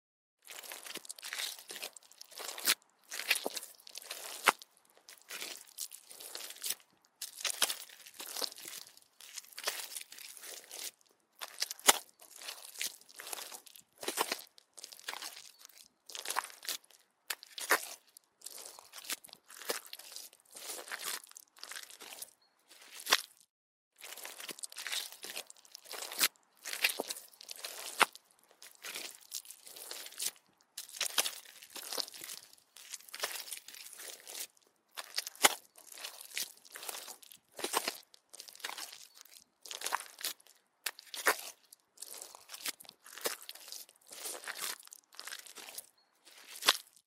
Tiếng Bước chân đi trên Đất Bùn, Ẩm ướt…
Tiếng Bước Chân đi trong Bùn nhão, Đầm lầy, dưới Nước… Tiếng Bước Chân đi trong Bùn đất nhão, ướt át….
Thể loại: Tiếng động
Description: Tiếng bước chân trên đất bùn lầy lội vang lên lóp nhóp, lép nhép, bì bõm, sền sệt, nhão nhoét, sũng nước, gợi cảm giác ẩm ướt và nặng nề, tiếng giẫm, kéo lê, sục sạo trong sình lầy, thêm lẹp xẹp của dép, tiếng nước tóe... Âm thanh chân thực, sinh động, hút khán giả ngay lập tức.
tieng-buoc-chan-di-tren-dat-bun-am-uot-www_tiengdong_com.mp3